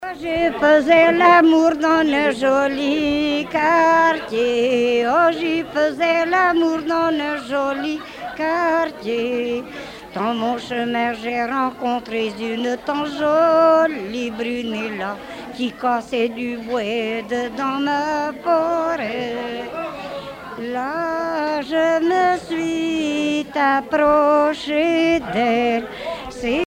Chansons traditionnelles
Pièce musicale éditée